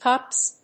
/kʌps(米国英語)/